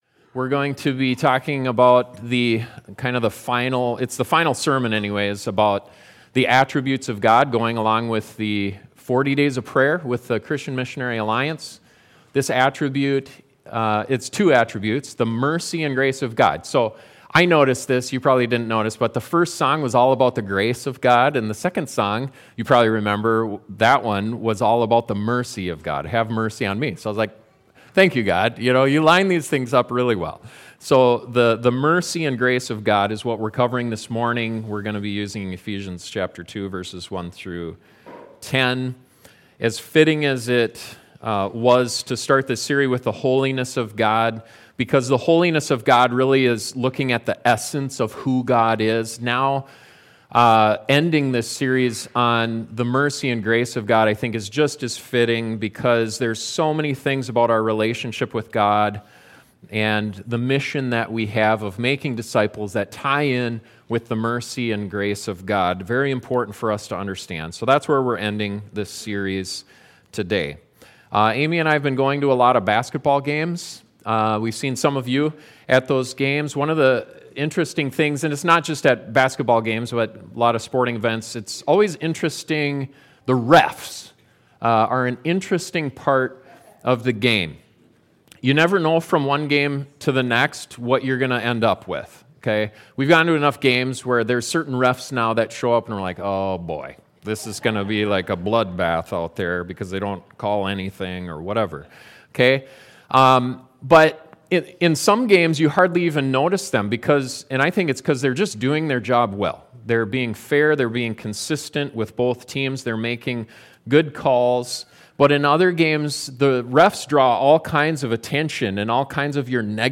But God is not only just, he is also merciful and gracious. This sermon explores the distinctions of these beautiful attributes.